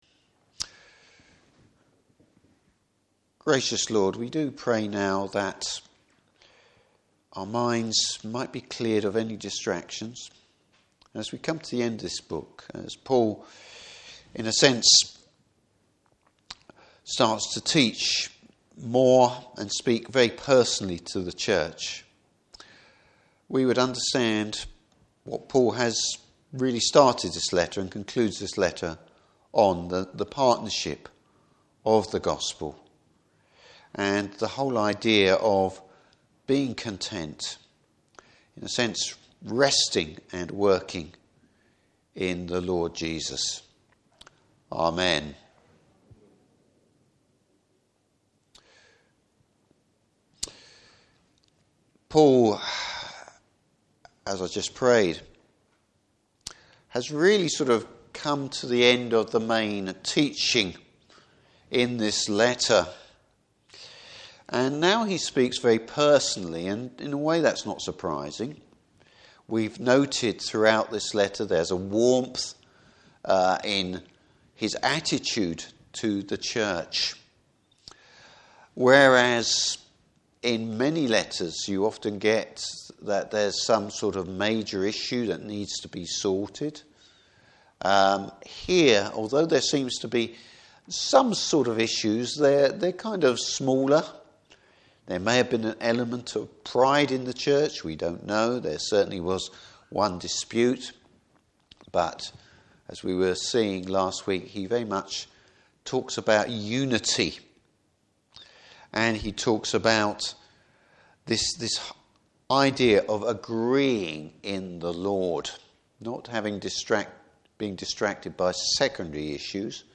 Service Type: Evening Service Fellowship and support for the work of the Gospel.